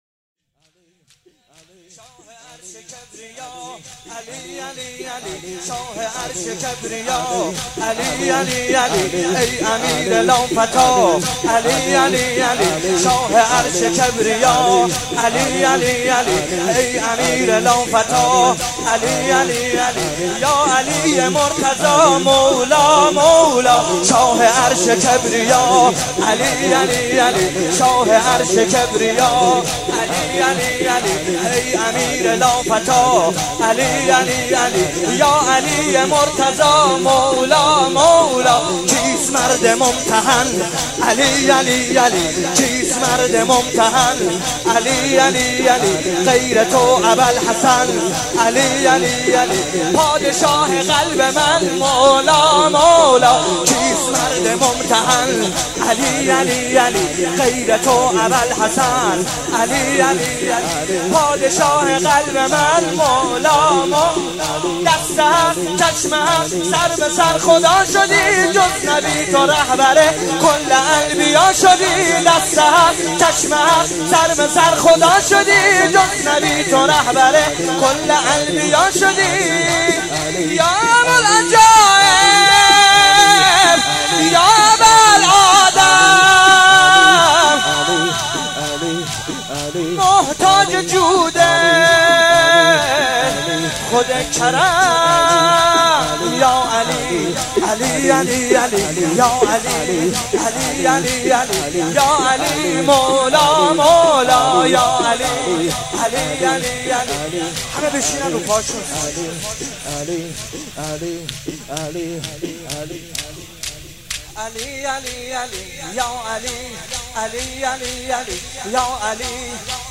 مداح
مناسبت : شب بیستم رمضان
قالب : شور